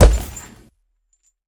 Minecraft Version Minecraft Version snapshot Latest Release | Latest Snapshot snapshot / assets / minecraft / sounds / mob / ravager / step4.ogg Compare With Compare With Latest Release | Latest Snapshot
step4.ogg